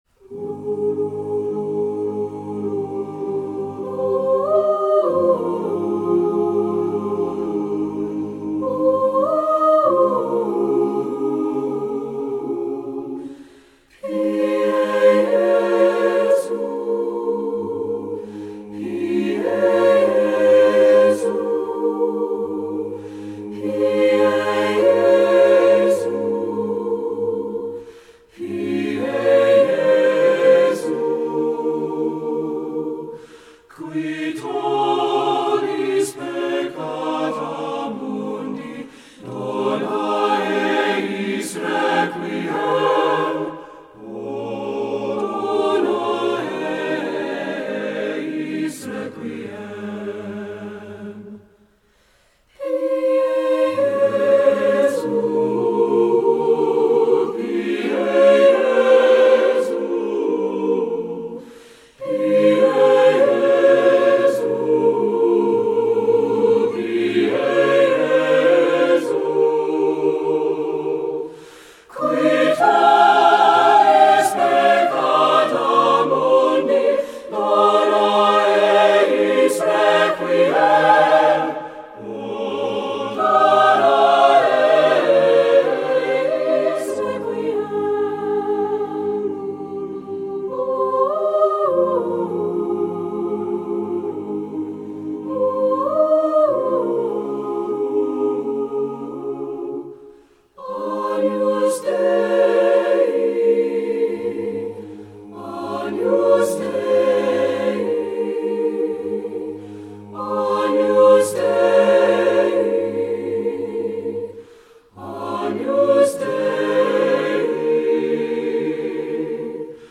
Voicing: TTBB a cappella